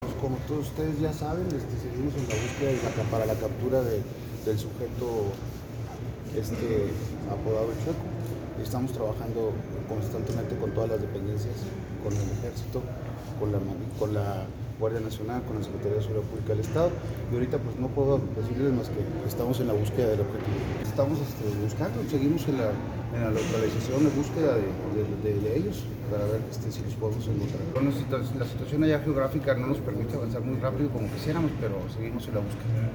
Audio. Fiscal general Roberto Fierro Duarte.
Fierro-Duarte-sobre-busqueda-de-El-Chueco.mp3